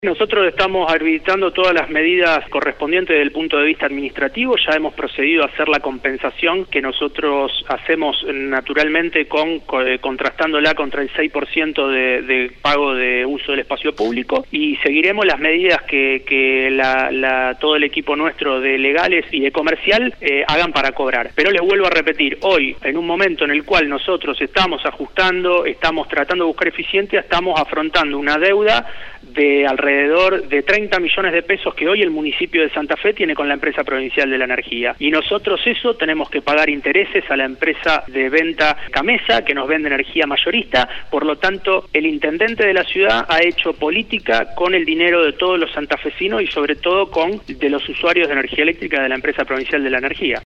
El Presidente de la Empresa Provincial de la Energía, Maximiliano Neri realizó un fuerte descargo a través de Radio EME tras la filtración de los supuestos sueldos que cobran los directivos de la distribuidora.